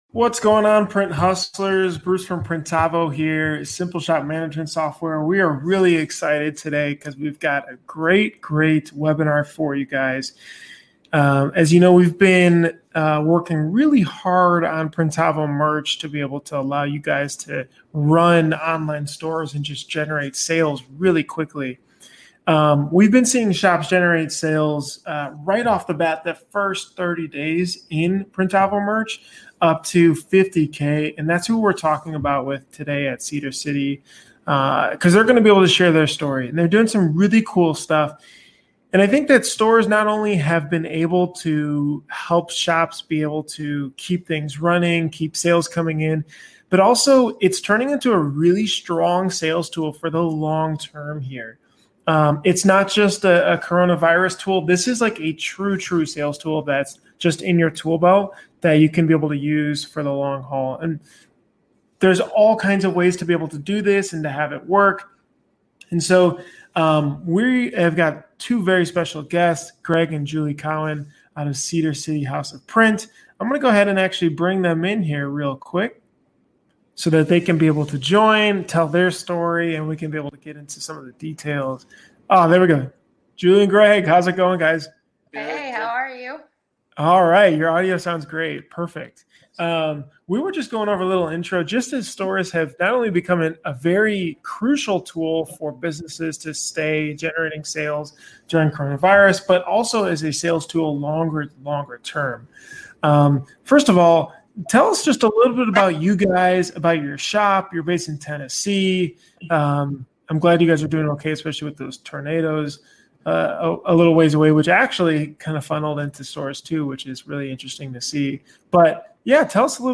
We join Cedar City House of Print for a candid interview about their upstart screen printing business in Tennessee.